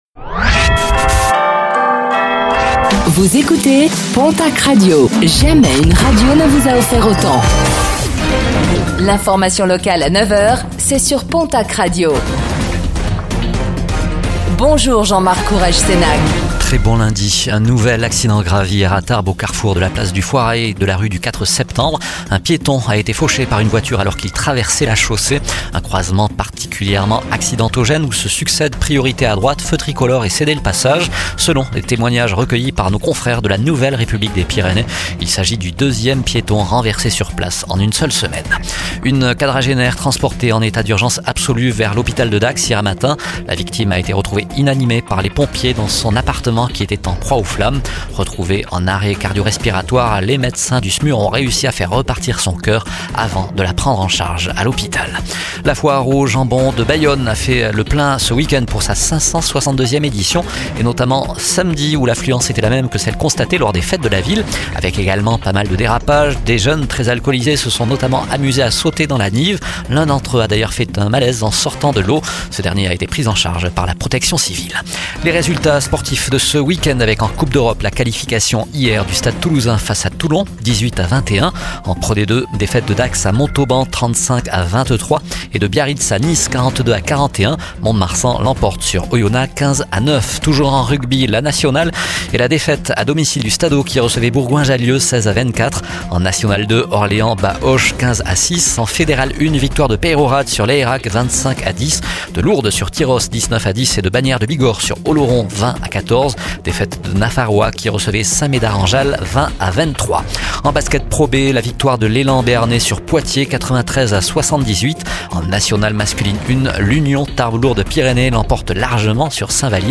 Infos | Lundi 14 avril 2025